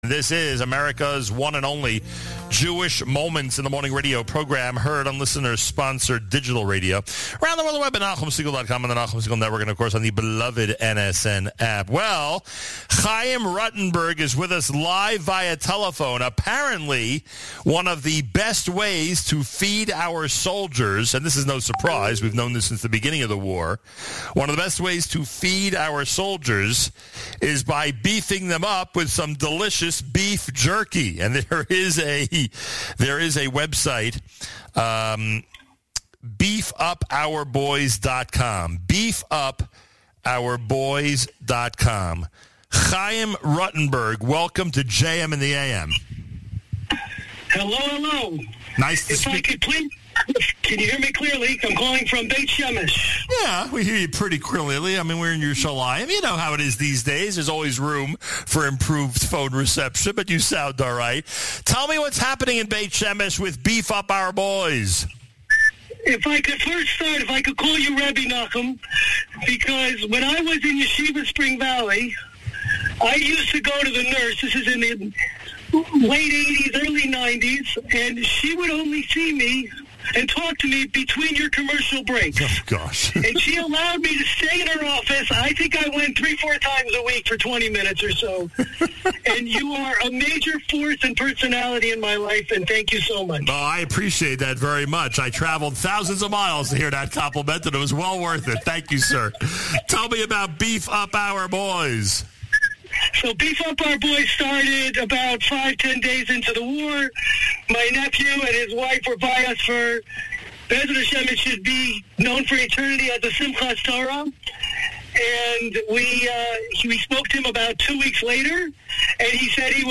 By :  | Comments : Comments Off on Beef Up Our Boys, Beef Jerky for Our IDF Soldiers, Featured on JM in the AM | Category : Interviews, Israel, News